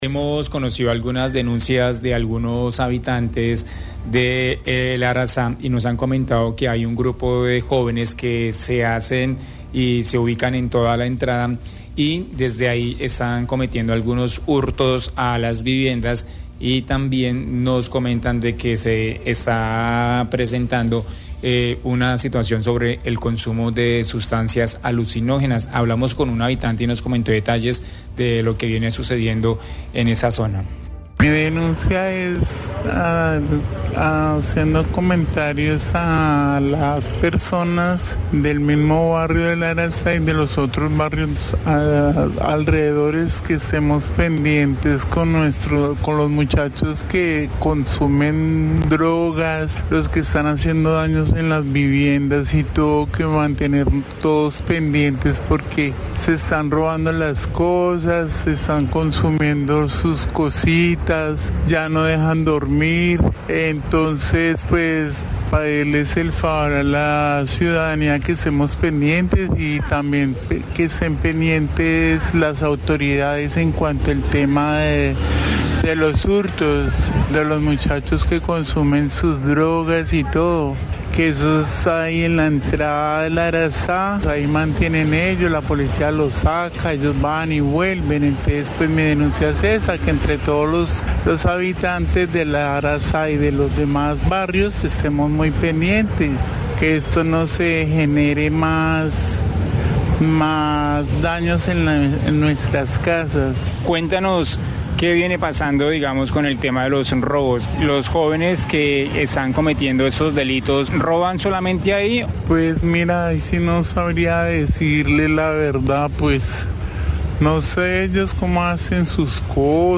Mujer relata robo a su vivienda en el asentamiento Arazá